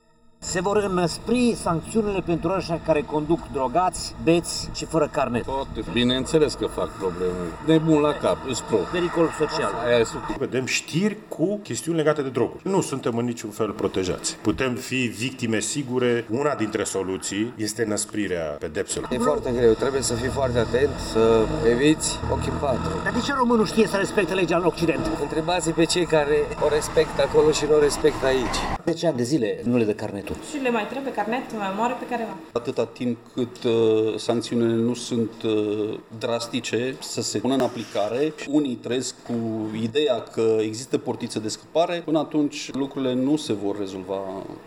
Conducătorii auto sunt categorici în privința aceasta, însă legea, oricât de aspră ar fi, trebuie și respectată, pentru că mulți șoferi contestă sancțiunea și scapă de pedeapsă: